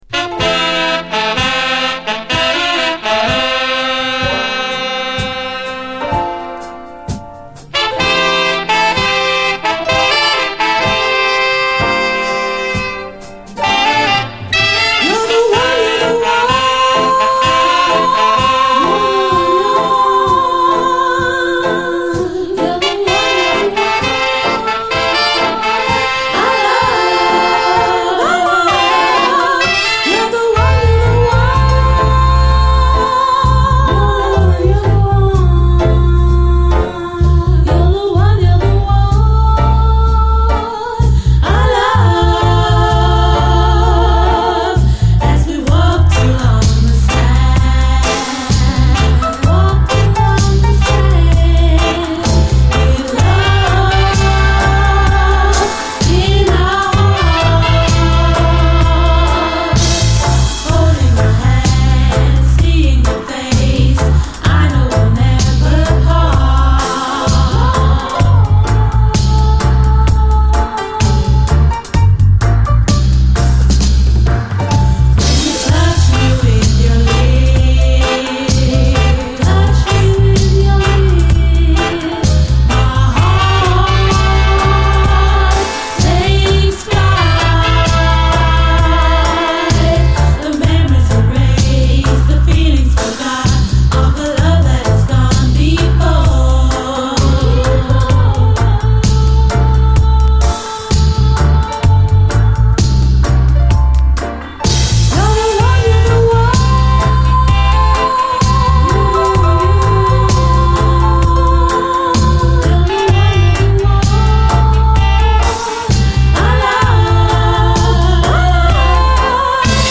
後半はダブに接続。